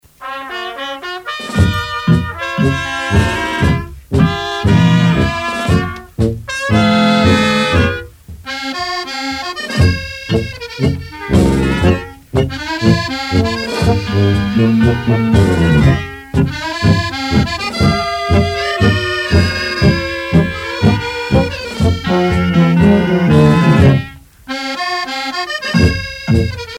tango musette
Pièce musicale éditée